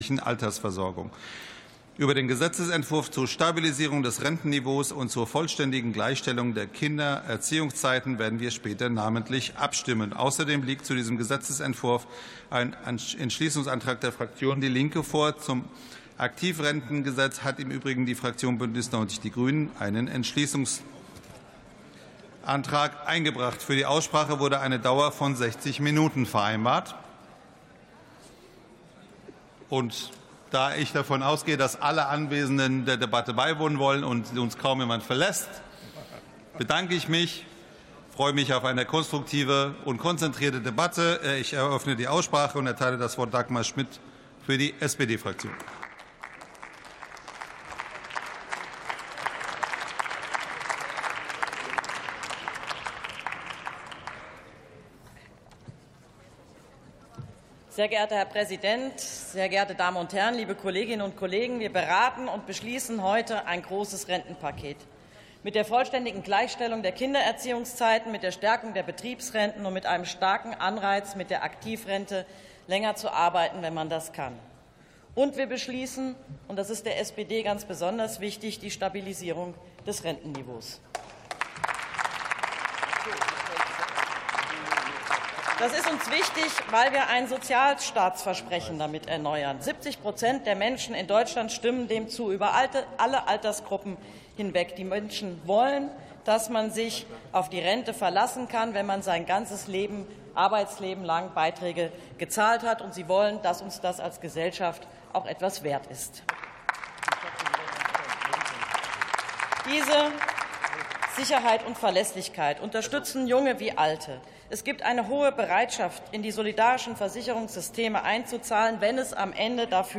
Plenarsitzungen